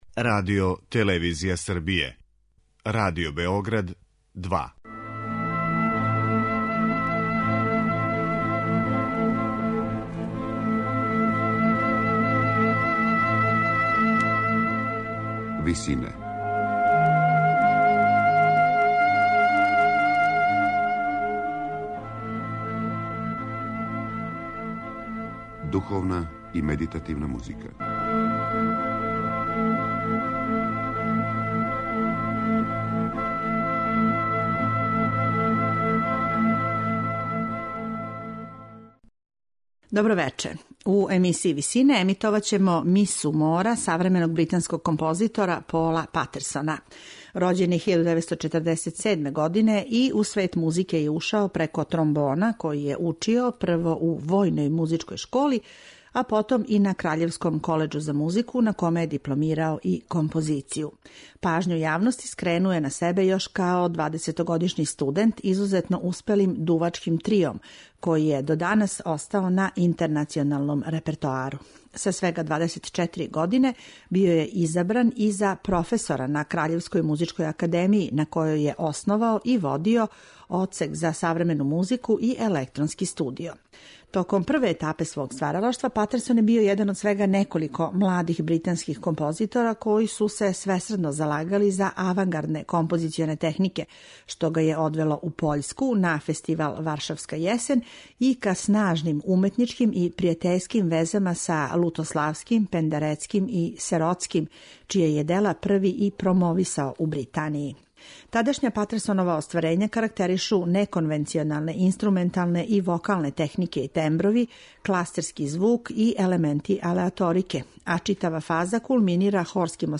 сопран
бас